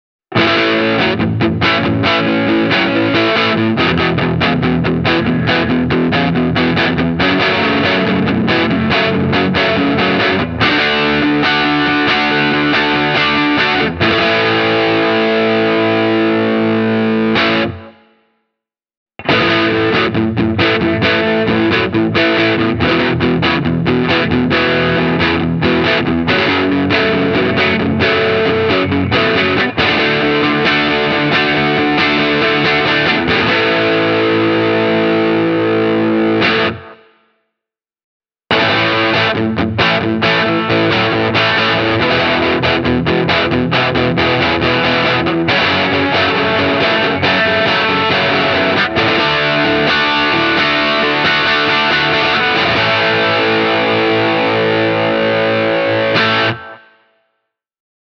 The crunchy tones of the Pat Smear Signature manage to combine clarity and Rock-muscle in a very appealing fashion: